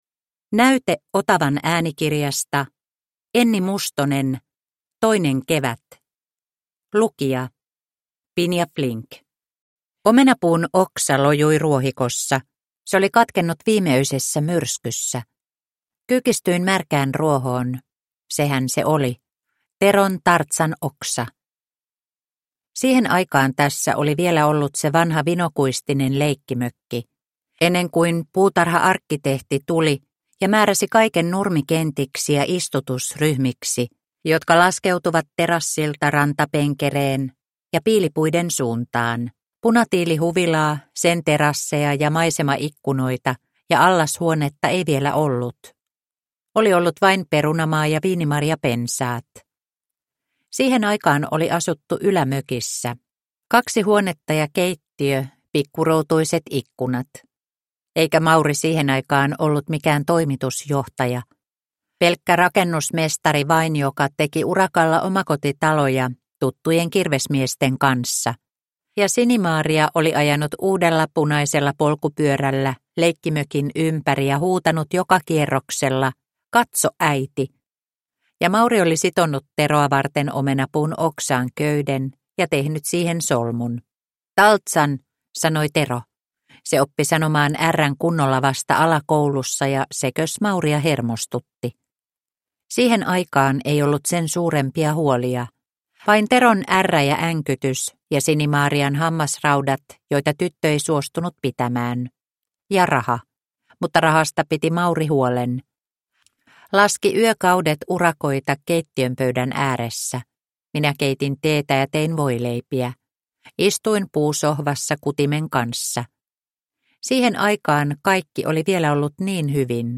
Toinen kevät – Ljudbok – Laddas ner